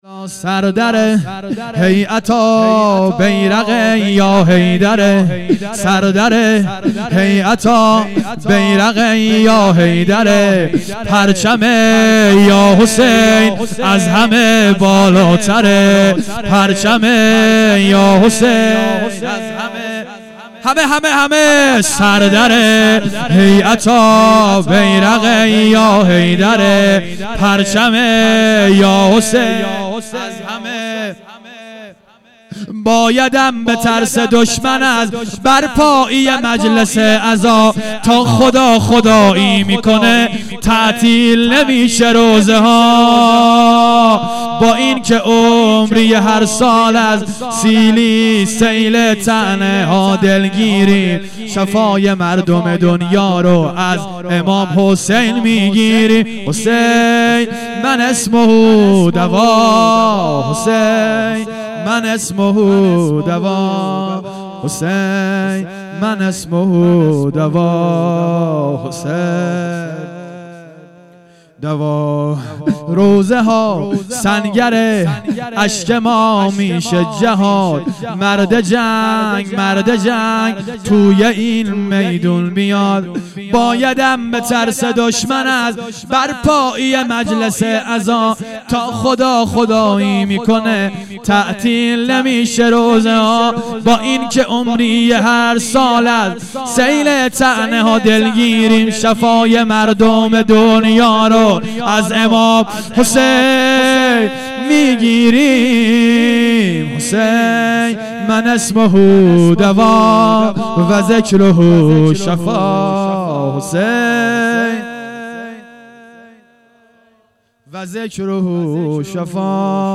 شب سیزدهم - دهه دوم محرم 1400